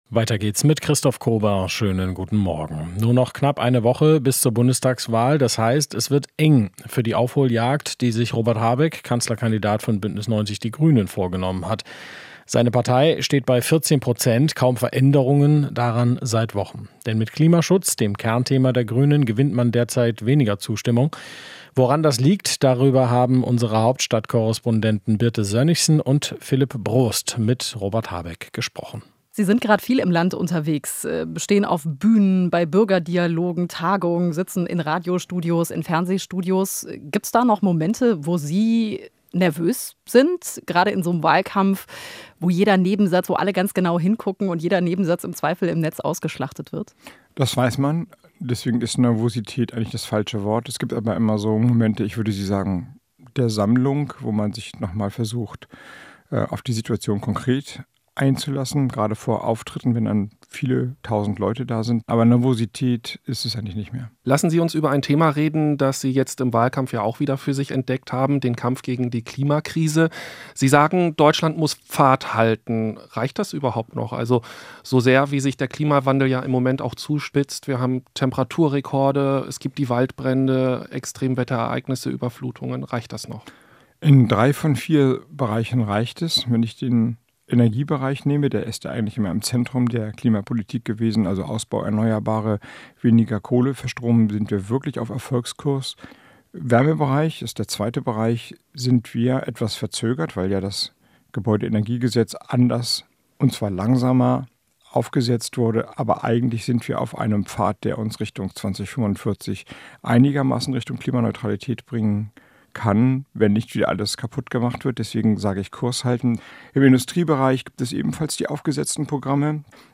Interview - Robert Habeck (Grüne): Kurs halten in der Klimapolitik